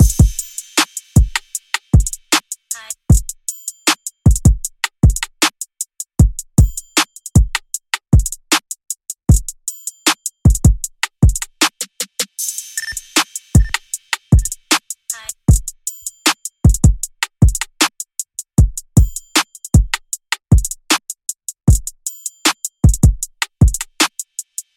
陷阱鼓循环播放02 帽子
描述：一个155 BPM的陷阱鼓循环，没有808。
Tag: 155 bpm Trap Loops Drum Loops 4.17 MB wav Key : Unknown